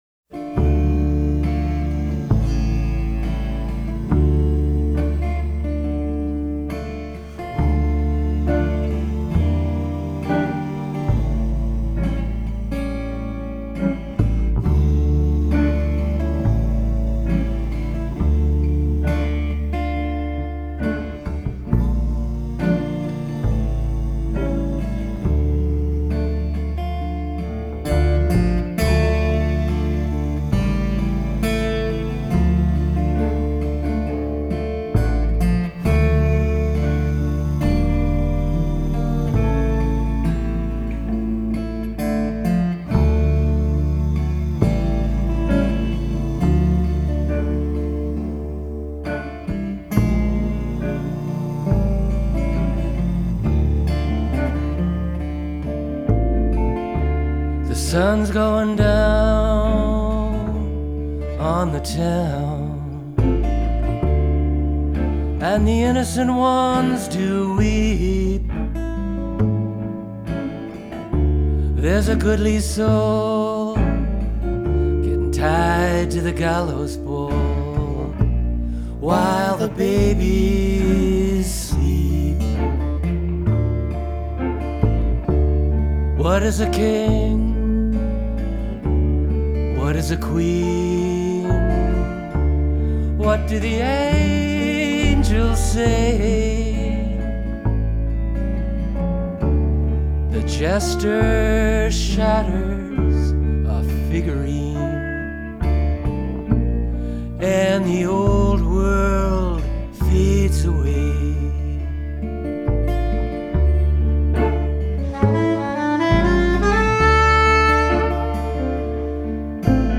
acoustic guitar/vocals
electric guitar/vocals
sax/vocals
bass/vocals